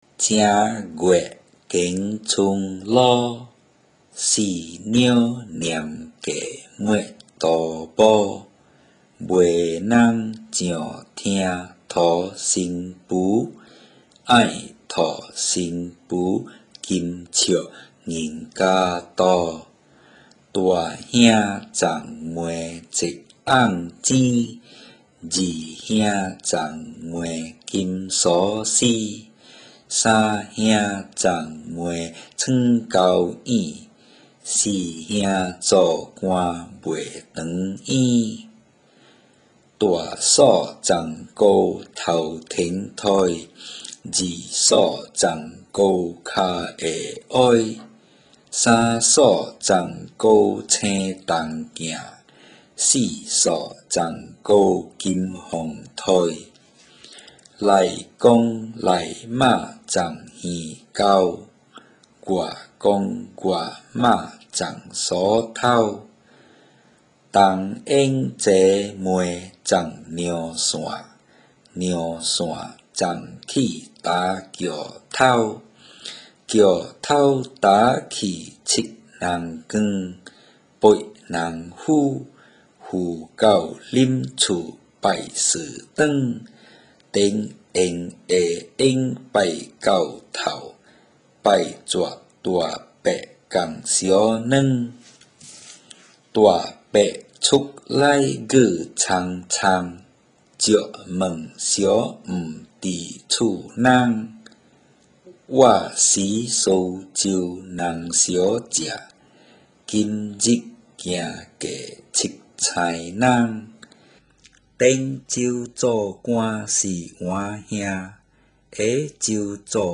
Click "Read" against each Teochew Nursery Rhyme to listen to it in normal Teochew.
GettingMarried_Read.mp3